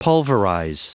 Prononciation du mot pulverize en anglais (fichier audio)
Prononciation du mot : pulverize